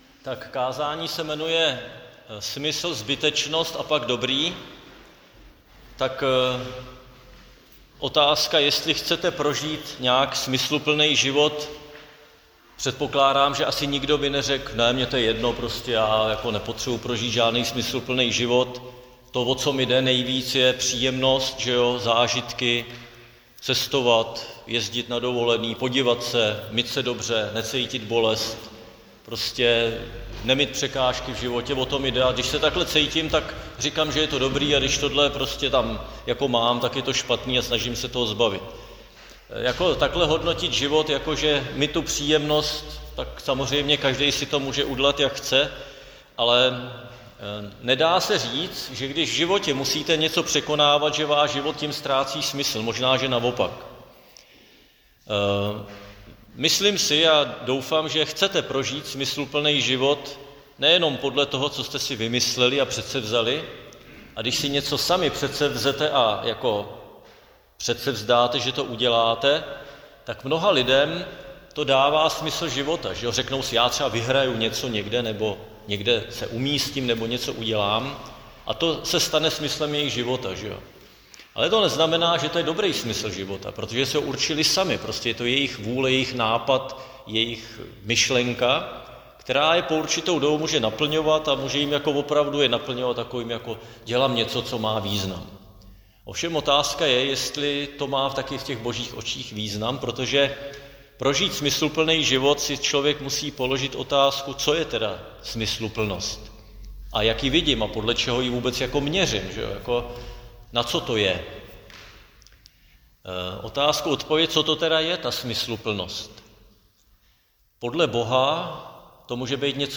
Křesťanské společenství Jičín - Kázání 27.7.2025